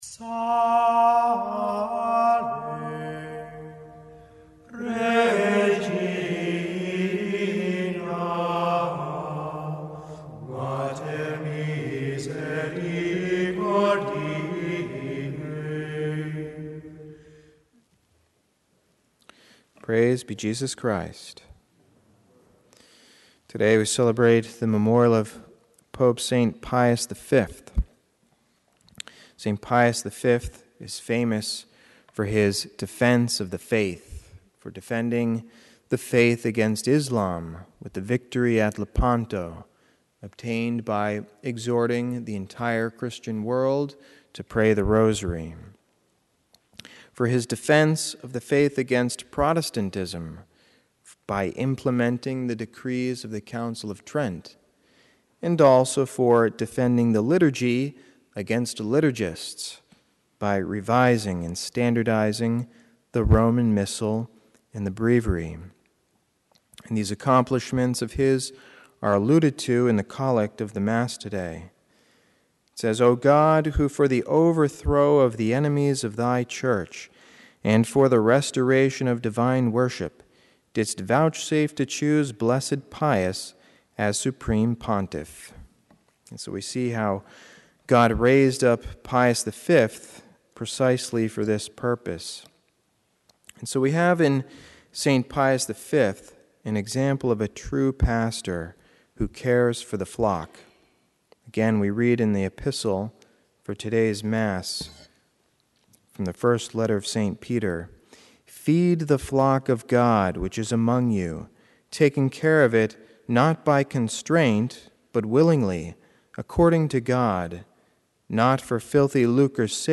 Homily
St. Pius V, Pope and Confessor - Mass: EF, Si Diligis - Readings: 1st: 1pe 5:1-4, 10-11 - Gsp: mat 16:13-19